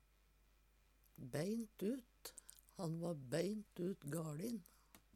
om ein vil understreke sterkt: "beint ut", elles "beintut"